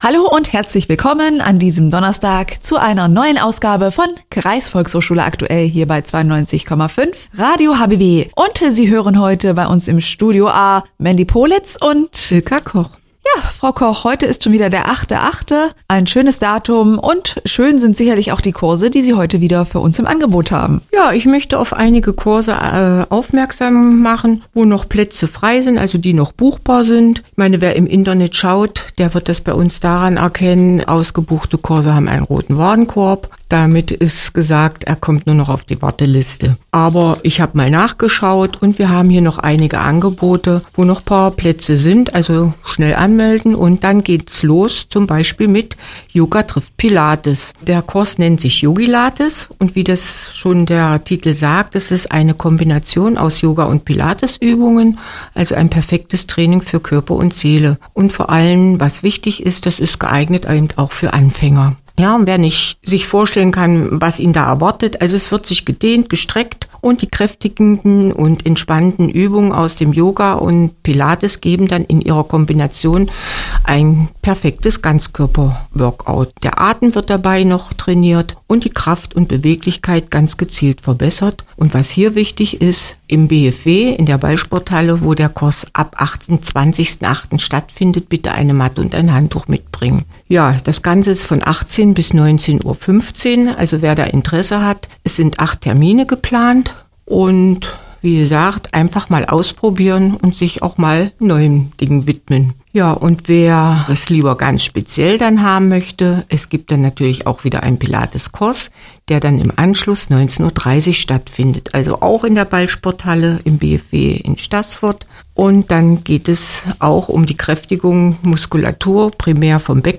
Hörbeitrag vom 8. August 2024